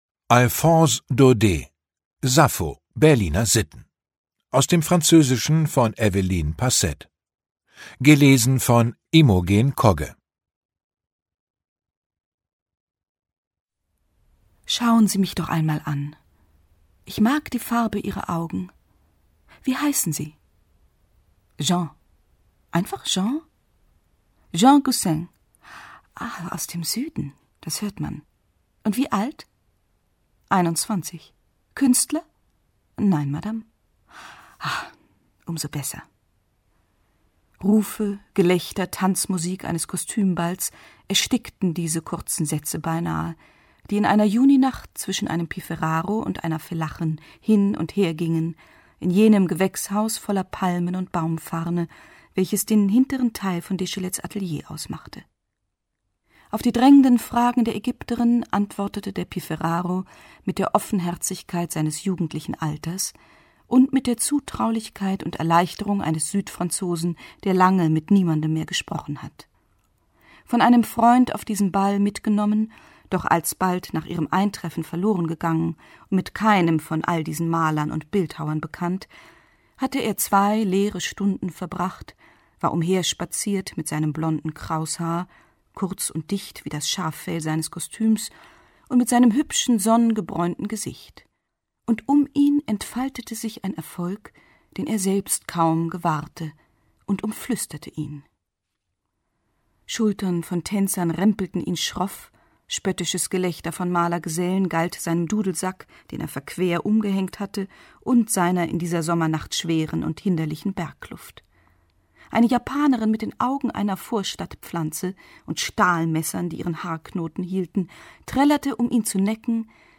Imogen Kogge (Sprecher)
Daudets bedeutendster Roman, erschienen 1884, wird einfühlsam gelesen von Imogen Kogge.